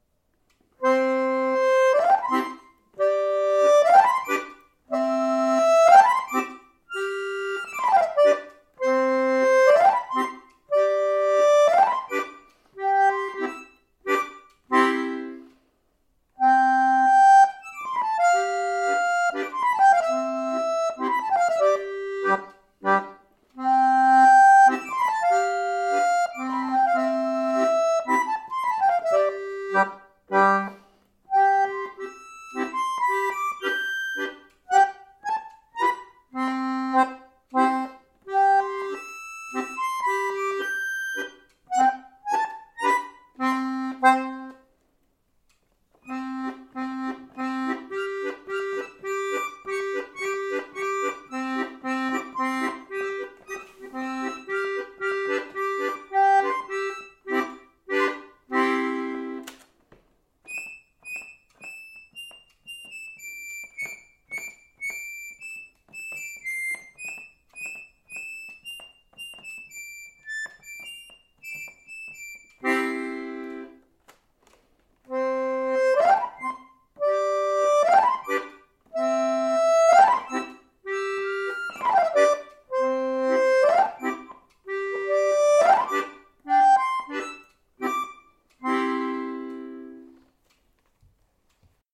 Staublunge) bin ich auf ein frühes Werk eines Meisters gestoßen: ...schon ewig nimmer gespielt... muss ich mal noch ein paarmal üben, damits wieder geschmeidiger läuft...